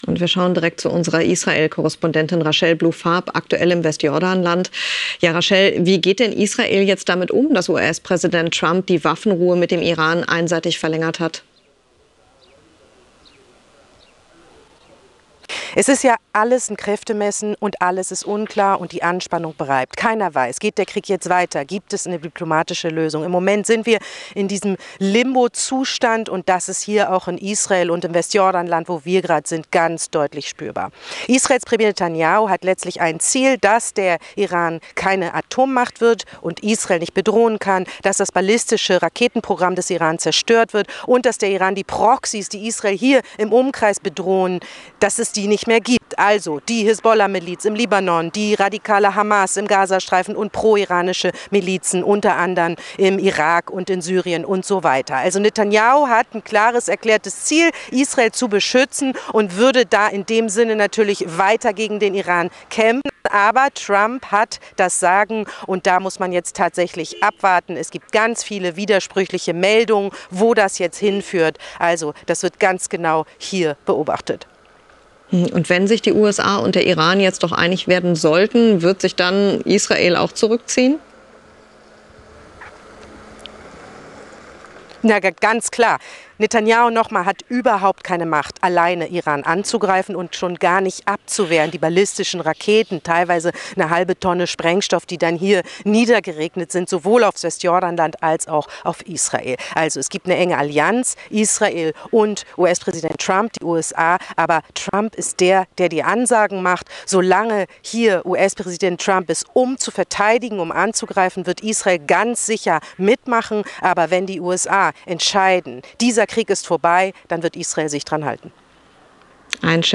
aus dem Westjordanland